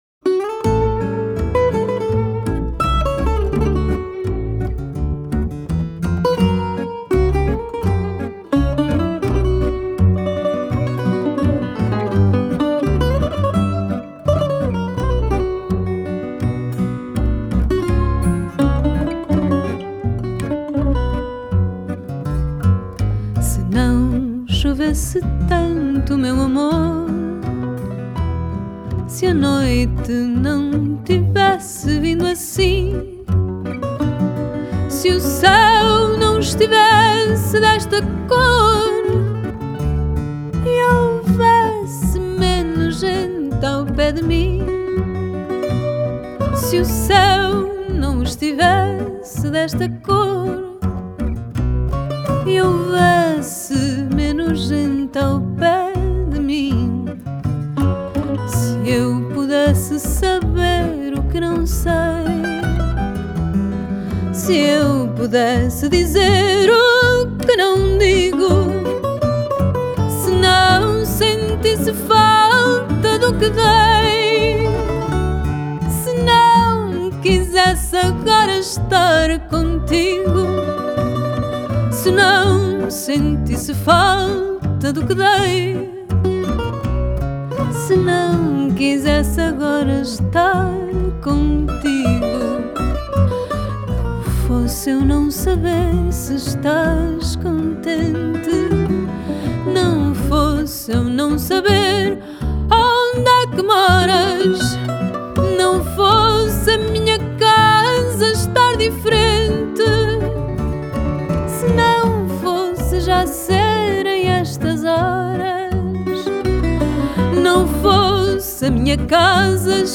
Portuguese Guitar
Double bass
Piano
Accordion